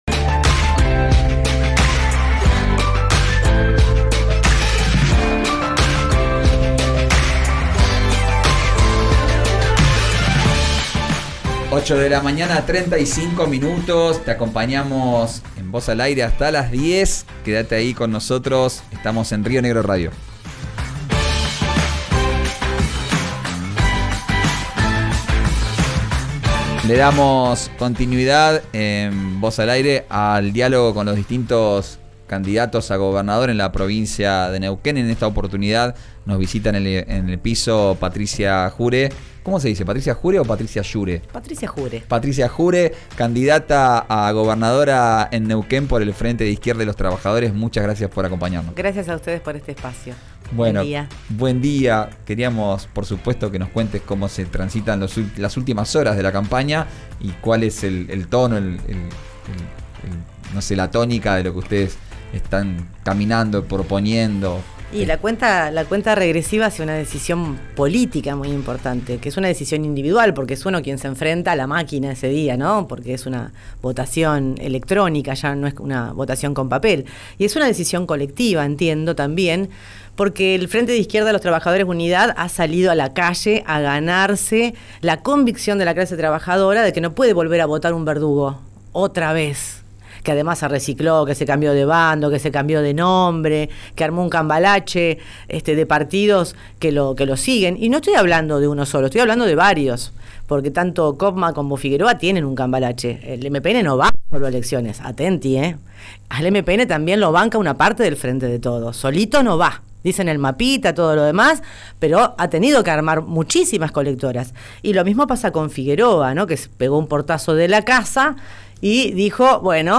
La candidata a gobernadora de Neuquén por el Frente de Izquierda y los Trabajadores visitó el estudio de RÍO NEGRO RADIO. Escuchá la entrevista completa en 'Vos al Aire'.